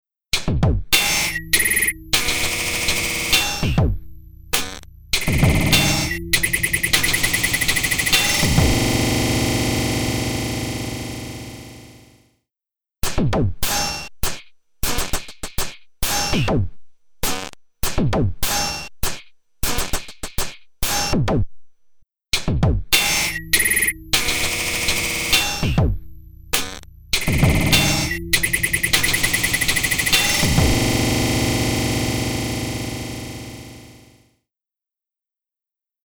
Massive Otherworldly Reverb
Blackhole | Drums | Preset: Oil Drum (Freeze Automated)
Blackhole-Eventide-Noise-Drums-Oil-Drum-Freeze-automated.mp3